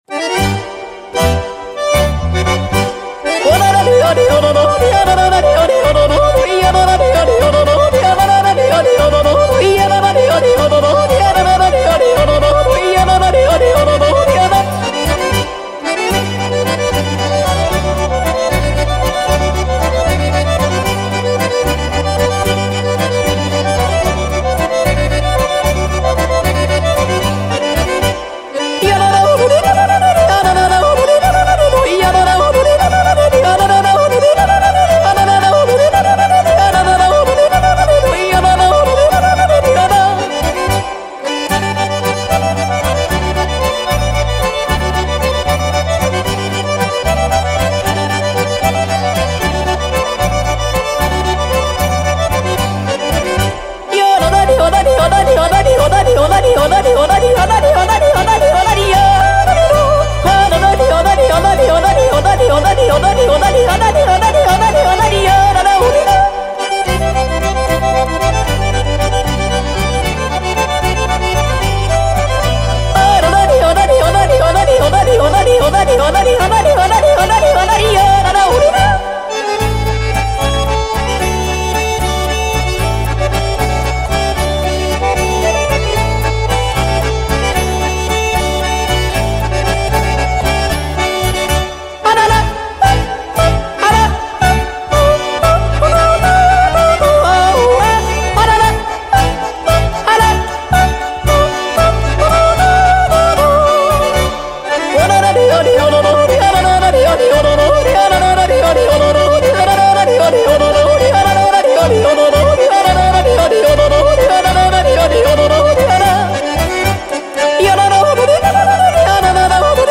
Swiss yodeling, popular songs and Swiss folk dances
Yodeling